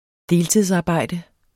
Udtale [ ˈdeːltiðs- ]